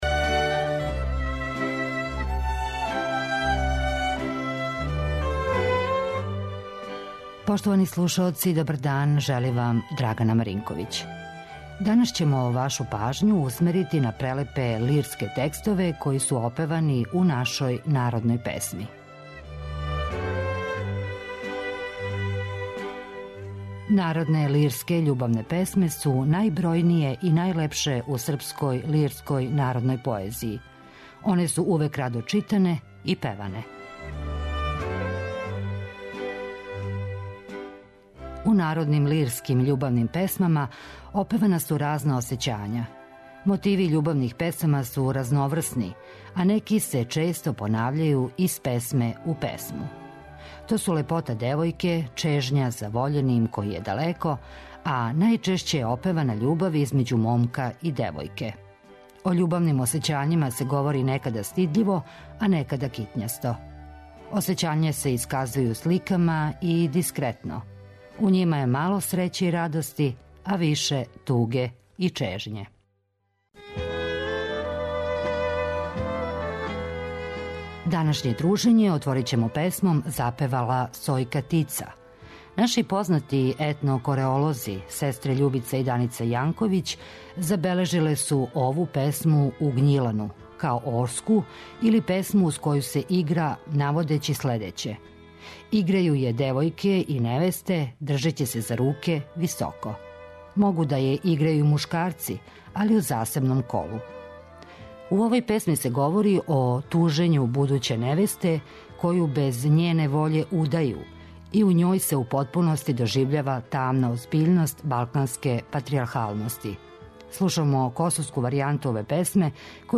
Данас ћемо вашу пажњу усмерити на прелепе лирске текстове који су опевани у нашој народној песми.